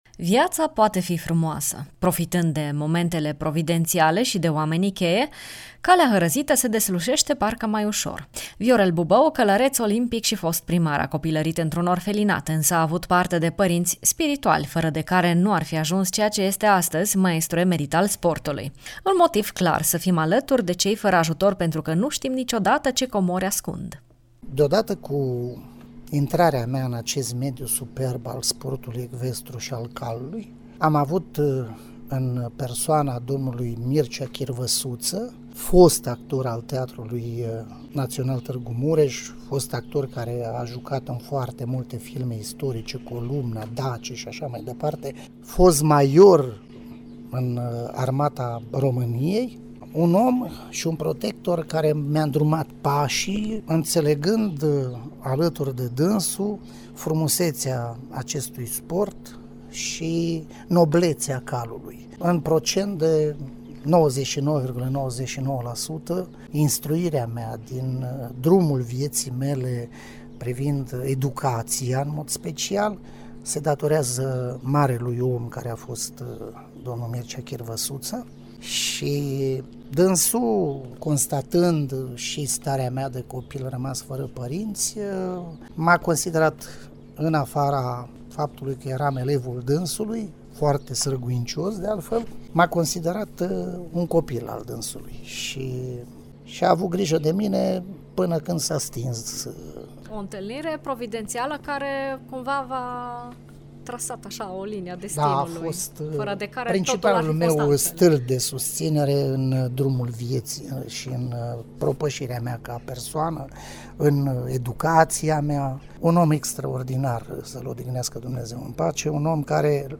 Invitatul de azi al rubricii Bucuria de a fi este exemplul omului care, pornind dintr-un loc lipsit de repere sigure, a știut să-și croiască singur un sens și un rost. A urcat treaptă cu treaptă, ghidat de oameni providențiali și de o voință ieșită din comun.